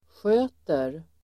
Uttal: [sj'ö:ter]